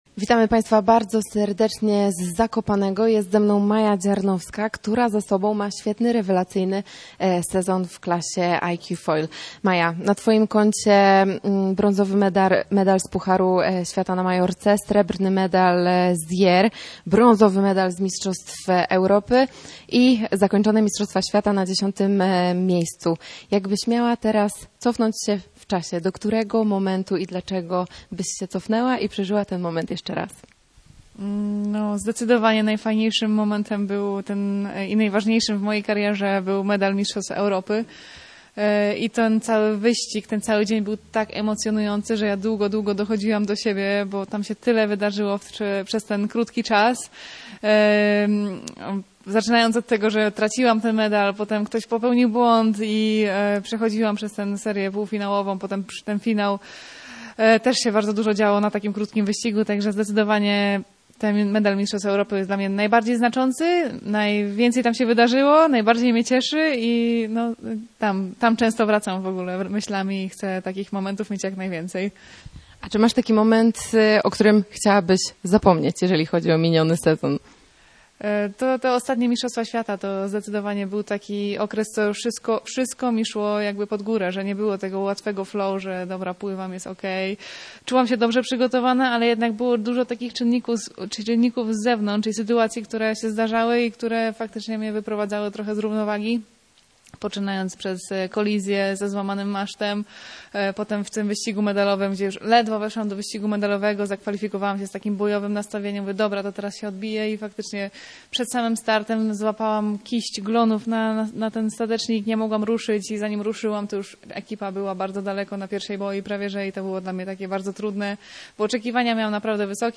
rozmawiała przed tygodniem w Zakopanem.